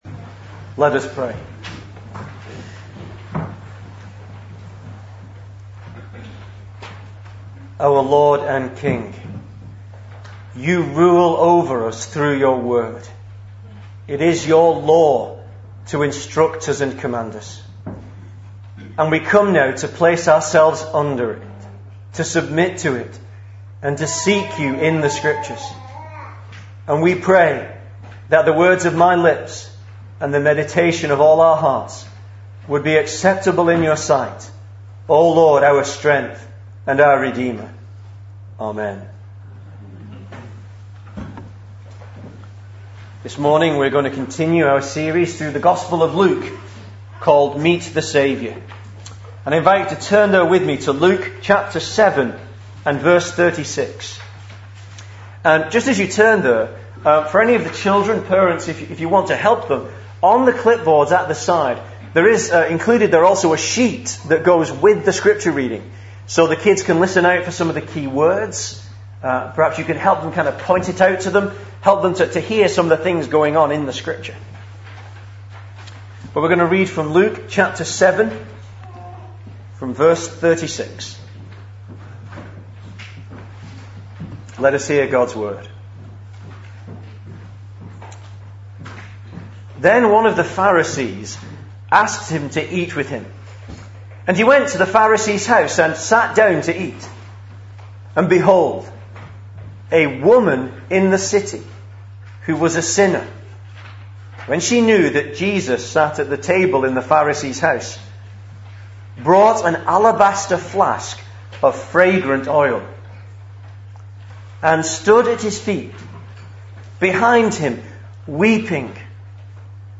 2013 Service Type: Sunday Morning Speaker